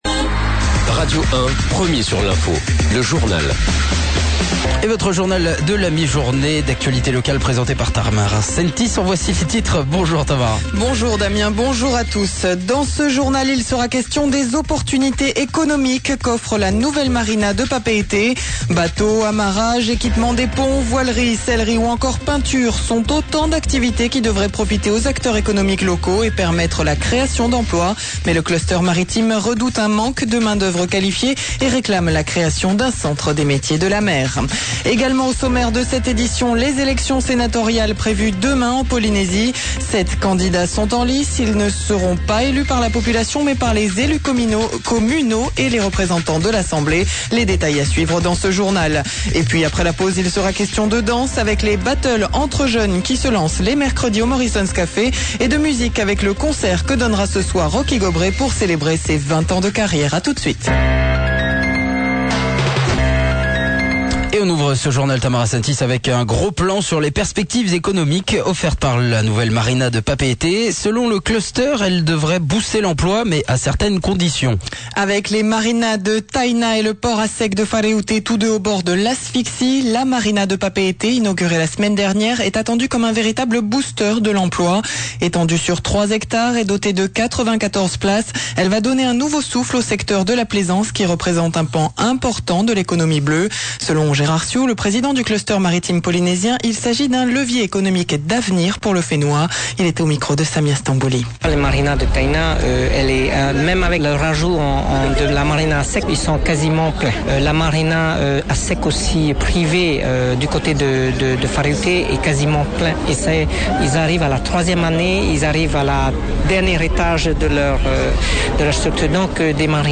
Journal de 12:00 le 02/05/15